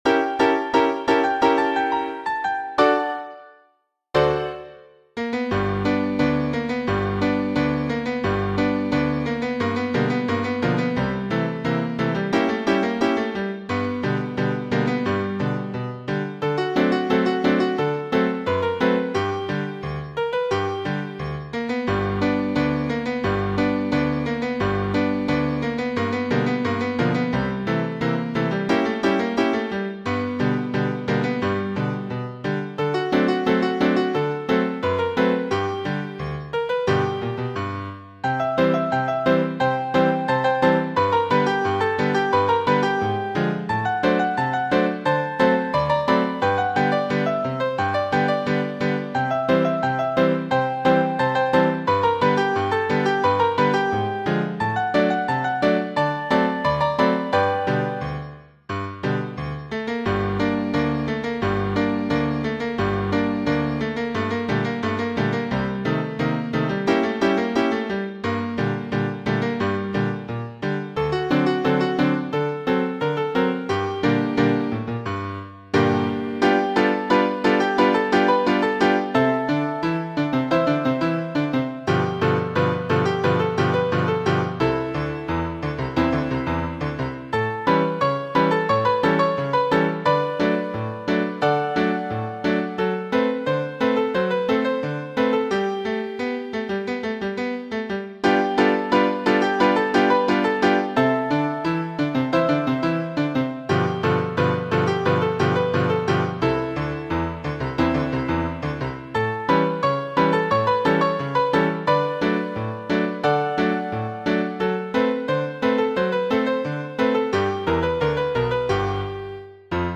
Blues en las ondas 555.ogg